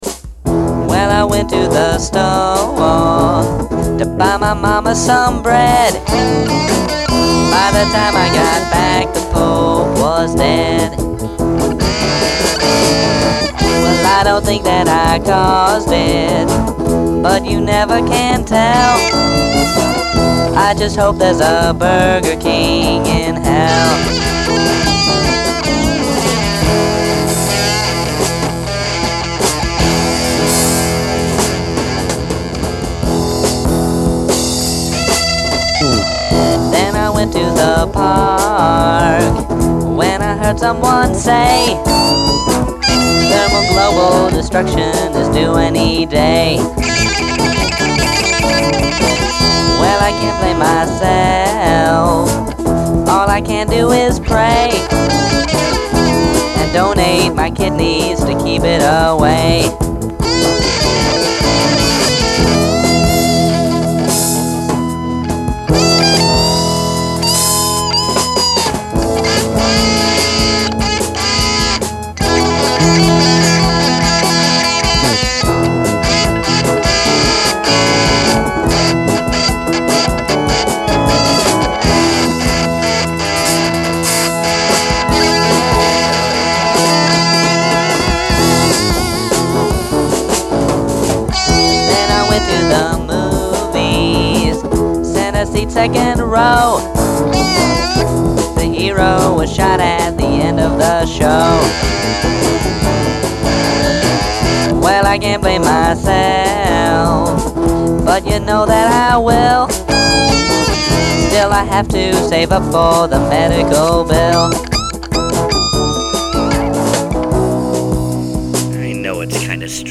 guitar solo